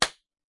拍手
描述：两只手拍一拍
Tag: 拍手 鼓掌